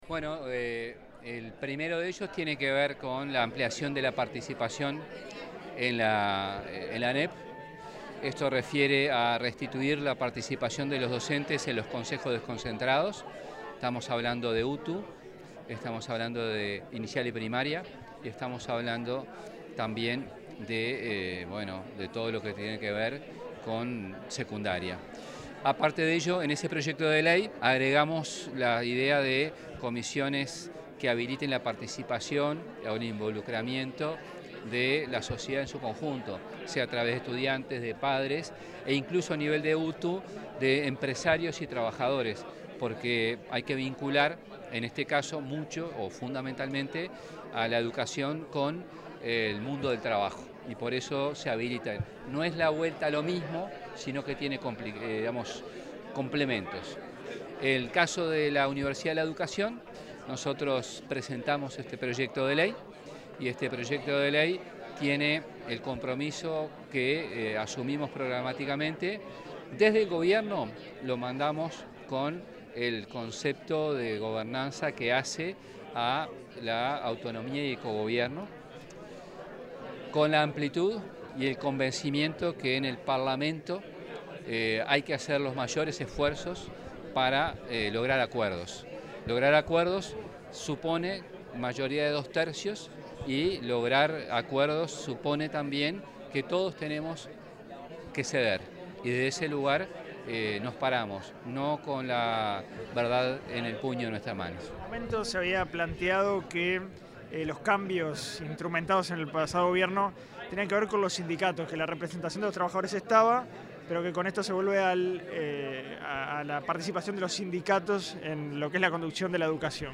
Declaraciones del ministro de Educación y Cultura, José Carlos Mahía
Una vez finalizada la presentación de los anteproyectos para la creación de la Universidad de la Educación y el aumento de la participación en la Administración Nacional de Educación Pública (ANEP), el ministro de Educación y Cultura, José Carlos Mahía, efectuó declaraciones a la prensa, en las que explicó el alcance de ambos contenidos.